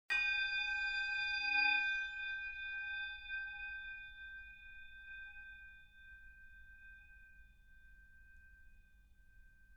ネットで見つけたホロフォニクスで録音されたと思われる音源。
ベルの音
Campanello.mp3